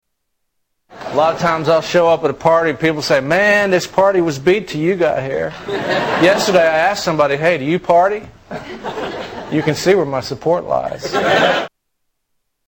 Category: Comedians   Right: Personal
Tags: Comedians Darrell Hammond Darrell Hammond Impressions SNL Television